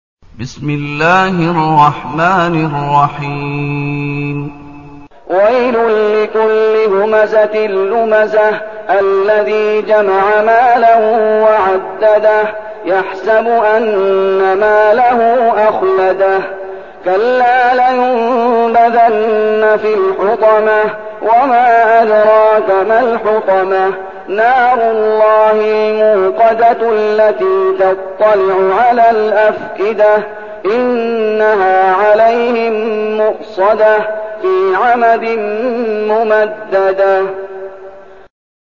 المكان: المسجد النبوي الشيخ: فضيلة الشيخ محمد أيوب فضيلة الشيخ محمد أيوب الهمزة